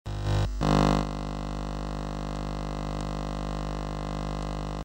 Звуки шипения телевизора
На этой странице собраны разнообразные звуки шипения телевизора: от едва заметного фонового шума до интенсивных помех.